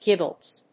/dʒíbləts(米国英語)/